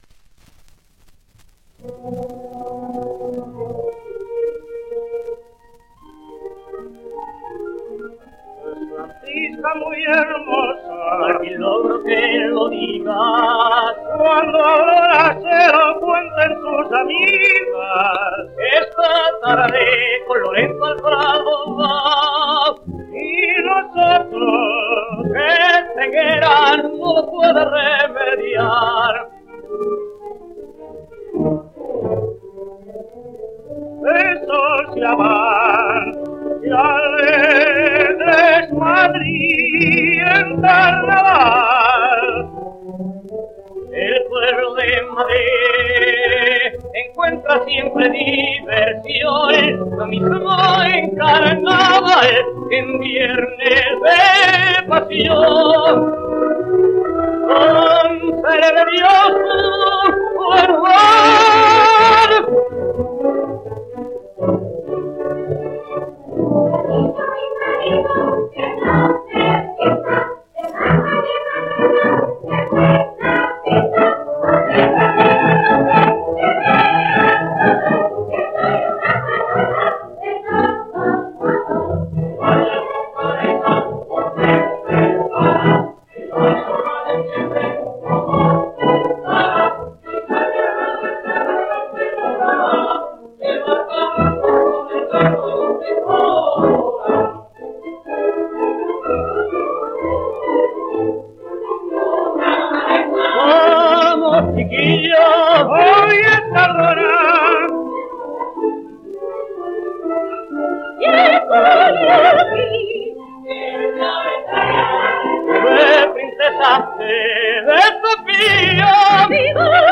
Doña Francisquita. Pasacalle. (sonido mejorado)
Editado por: Regal 8 discos : 78 rpm ; 30 cm